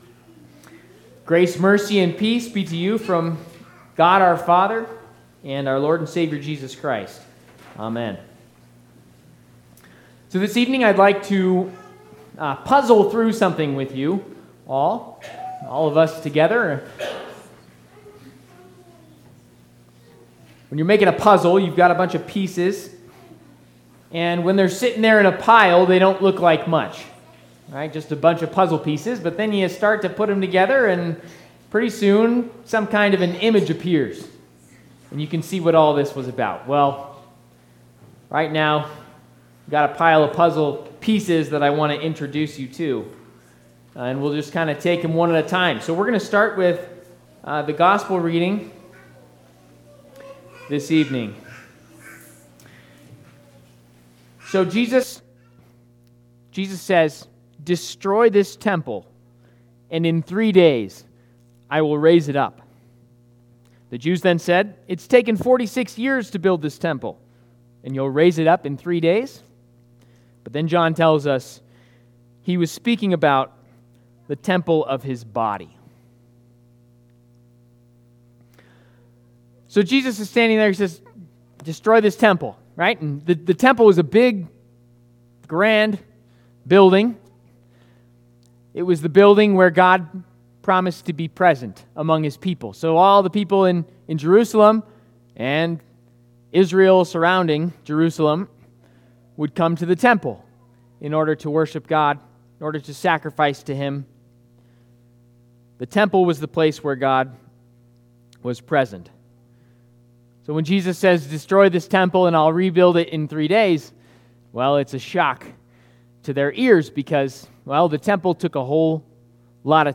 Advent 2 Midweek Service Sermon